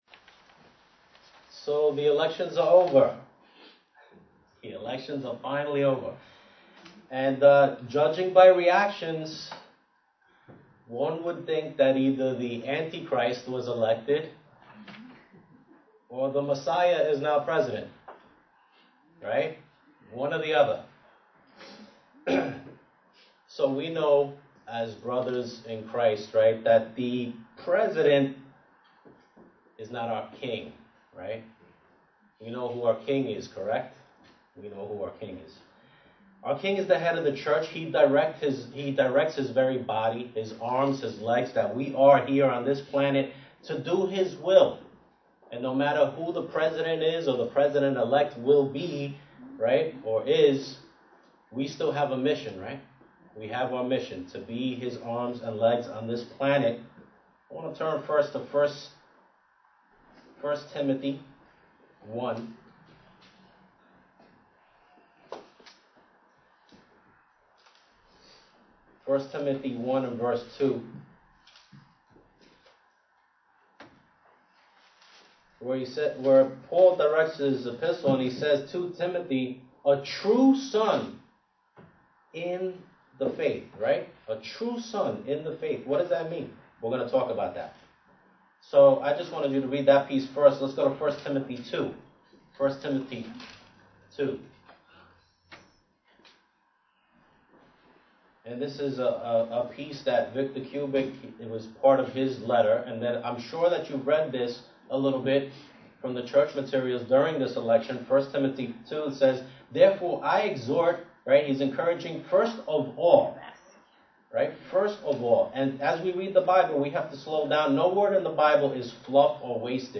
Sermons
Given in New York City, NY